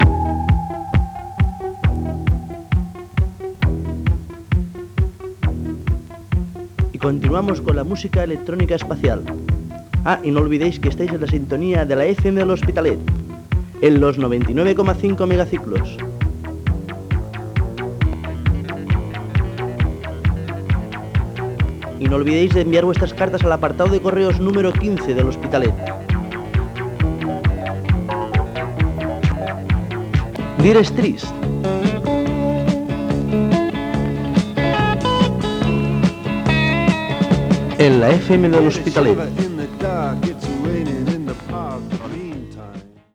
Identificació, adreça i tema musical.